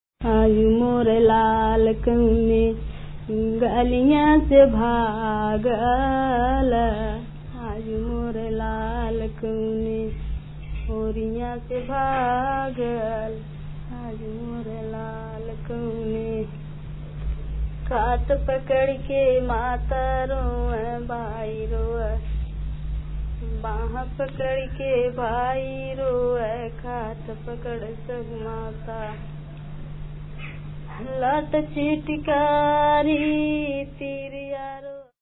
Folk Bhajan